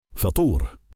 TranslitertionFuṭūr